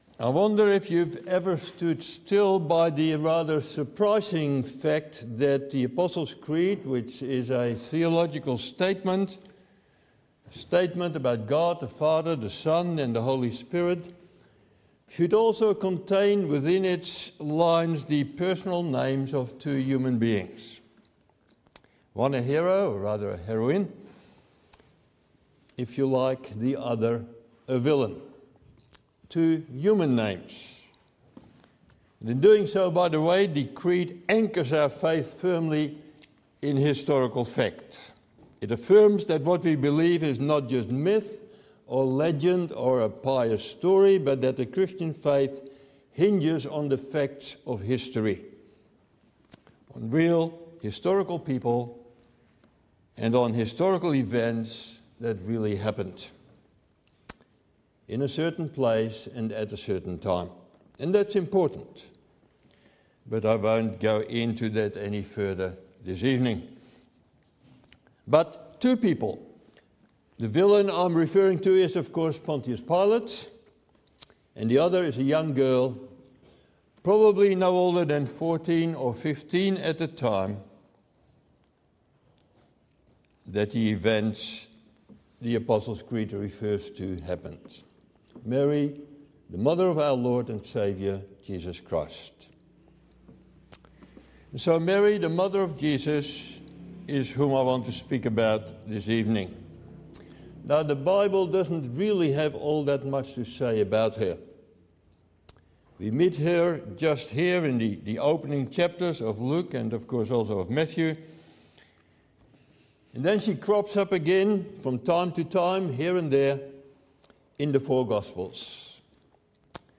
Sermons | Reformed Church Of Box Hill